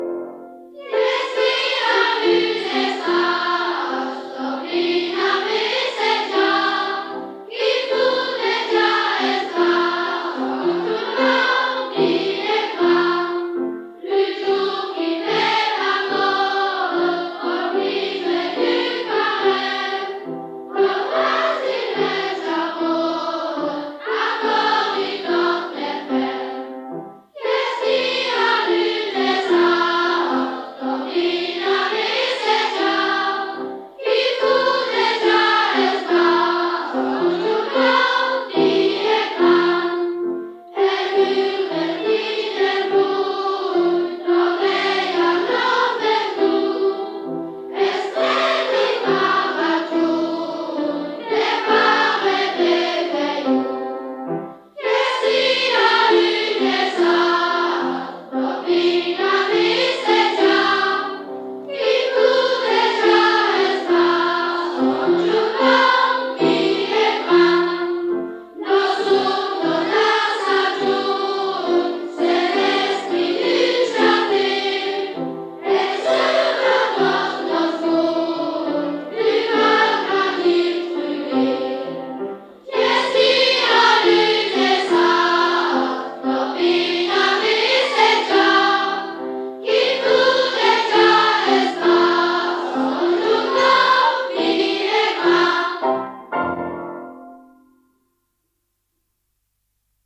Genre : chant
Type : chanson calendaire
Interprète(s) : Anonyme (enfant)
Lieu d'enregistrement : Malmedy
Support : bande magnétique
Chantée par une chorale d'enfants avec accompagnement au piano.